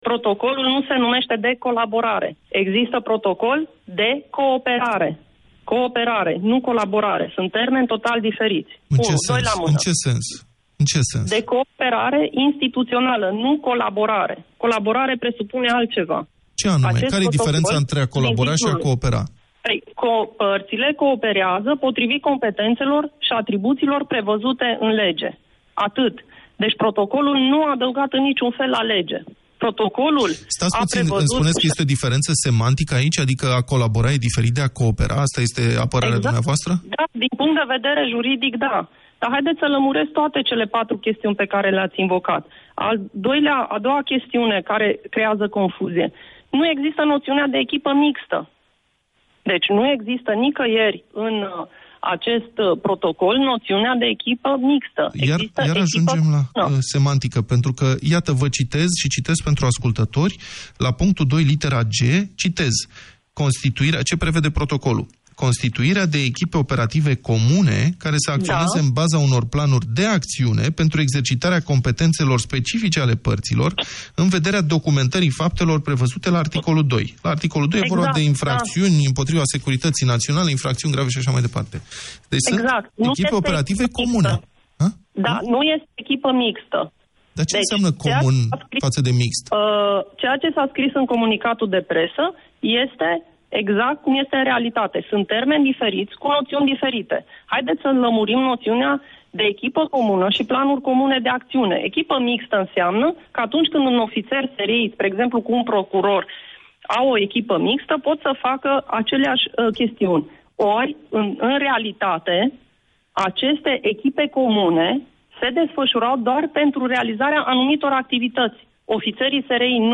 Procurorul șef al DNA, Laura Codruța Kovesi, a explicat în această dimineață, în Deșteptarea, la Europa FM, că protocolul cu SRI nu e unul de colaborare, ci de cooperare instituțională.